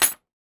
R - Foley 259.wav